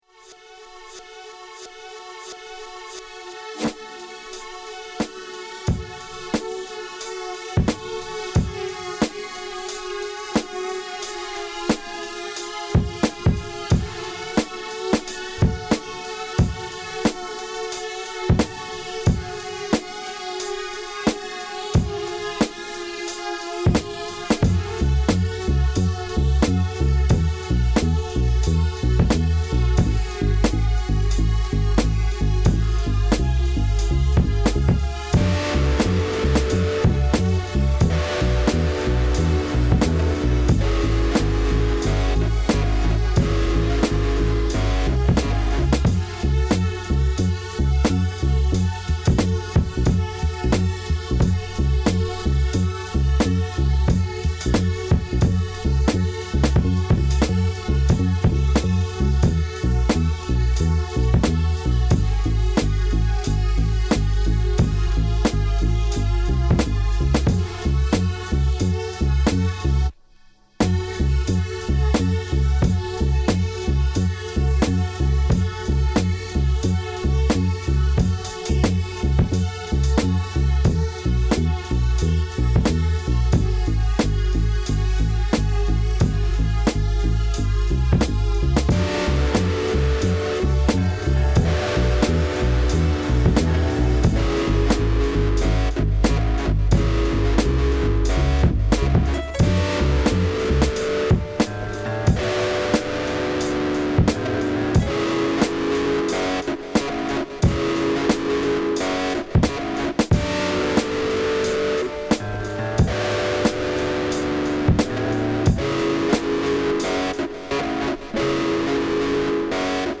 Get ready to dance along.
Hip Hop Jazz R&B Rap
highlighting the jazz influences throughout the genres.